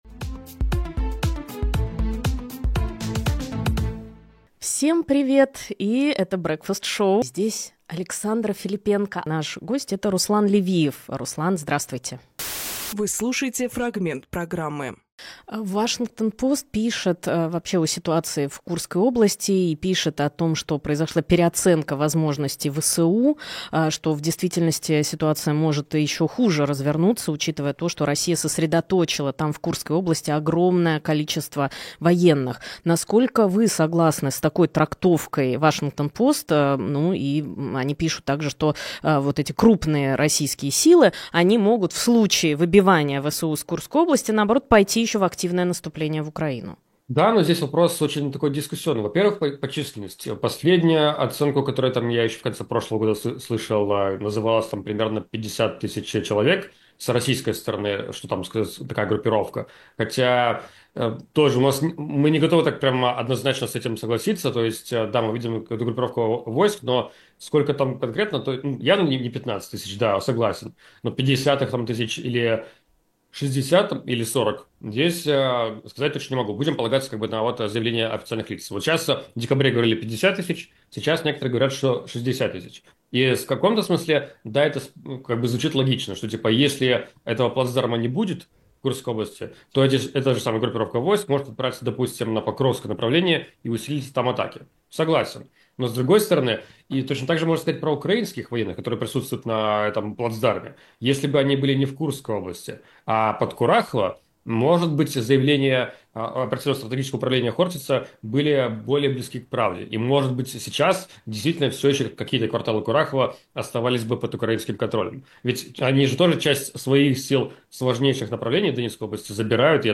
Фрагмент эфира от 12.01